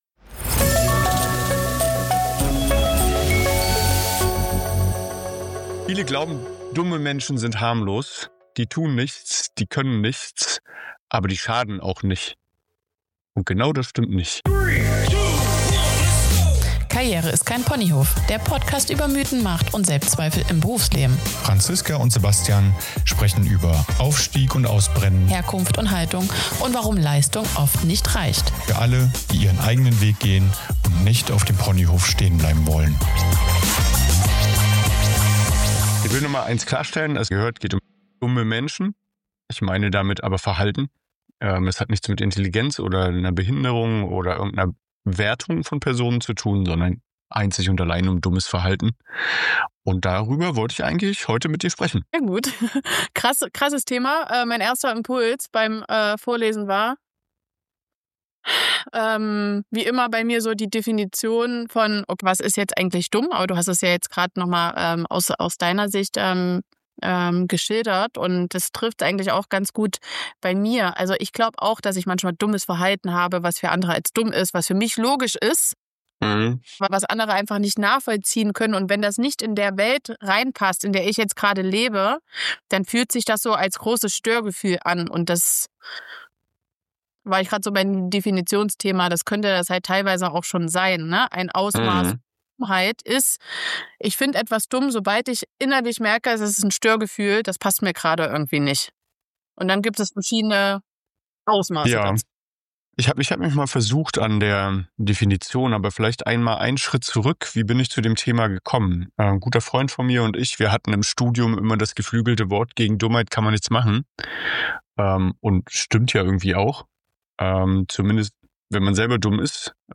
Beschreibung vor 3 Monaten Viele denken, dummes Verhalten im Berufsleben sei harmlos – wir widersprechen heute entschieden. In diesem Gespräch gehen wir den Ursachen, Definitionen und echten Folgen von Dummheit im Arbeitsalltag auf den Grund. Wir zeigen, warum nicht Intelligenz, sondern Denk-, Lern- und Verantwortungsverweigerung Unternehmen und Teams wirklich schadet.